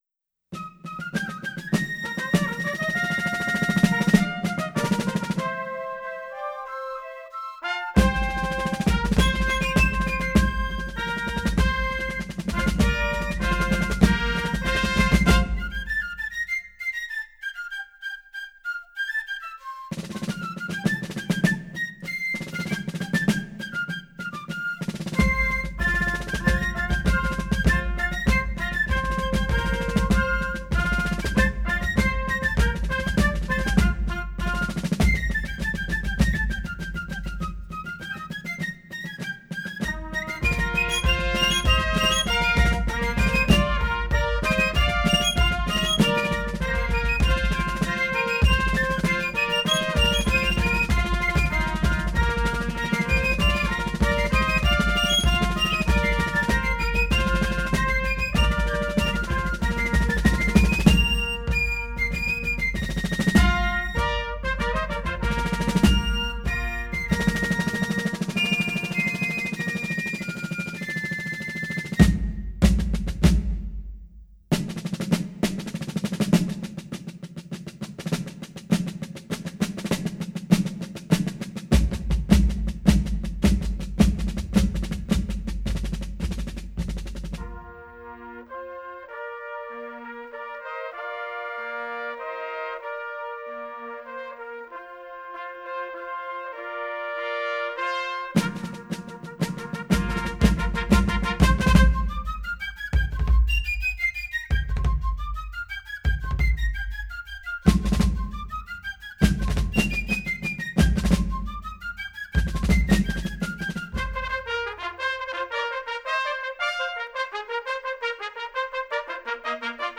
FifeAndDrumsStereo.wav